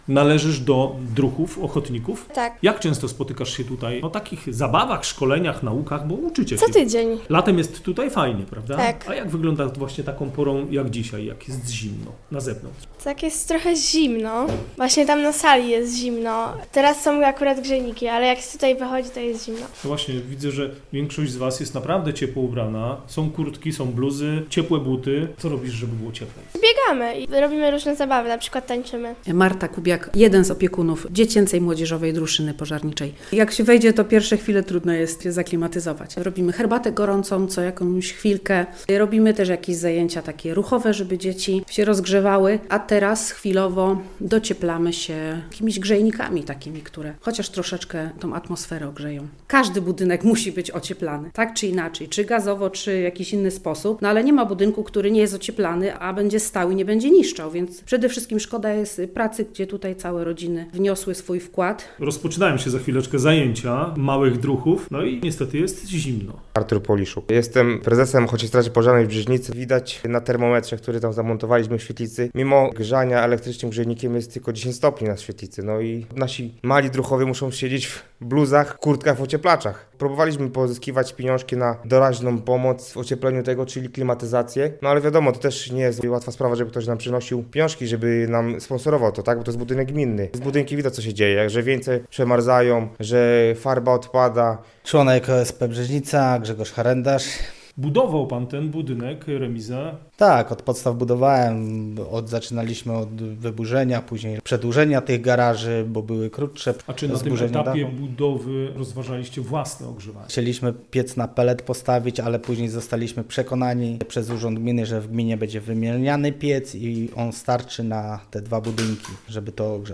Niestety kocioł dostarczający ciepło nie jest w stanie zapewnić właściwej temperatury w obu obiektach. – Dokumentacja na zadanie powstanie w pierwszych miesiącach nowego roku – mówi wójt Jerzy Adamowicz: